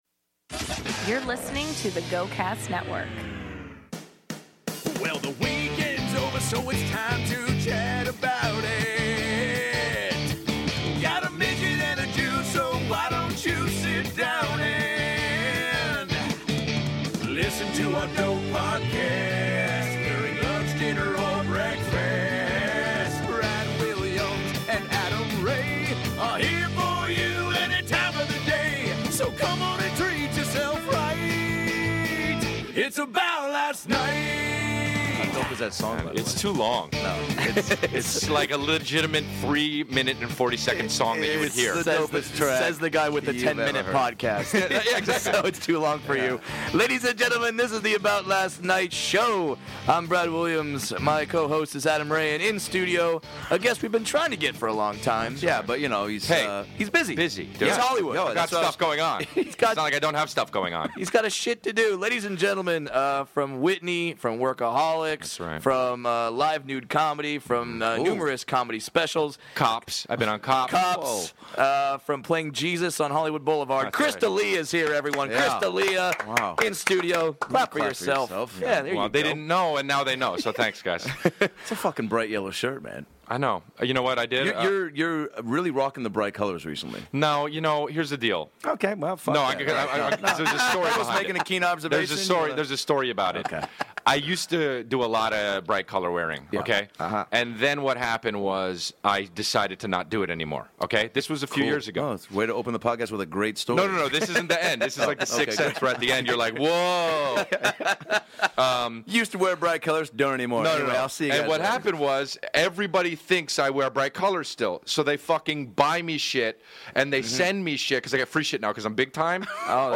freestyle rapping